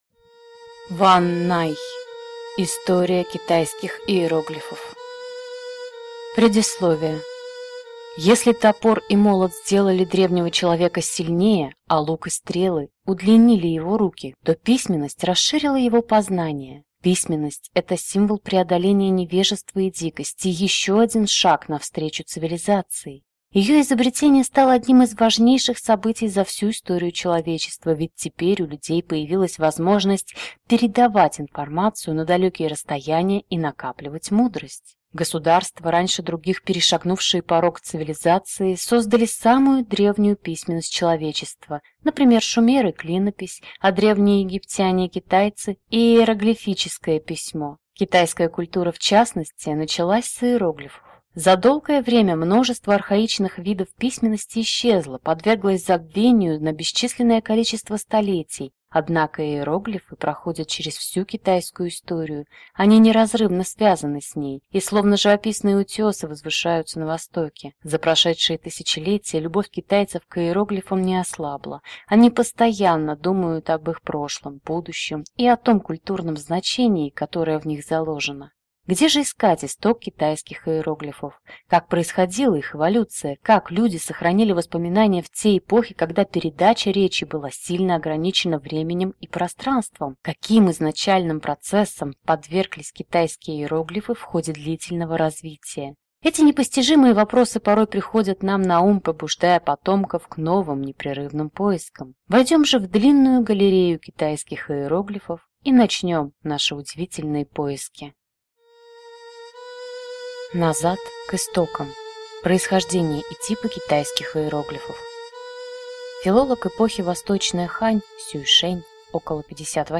Аудиокнига История китайских иероглифов | Библиотека аудиокниг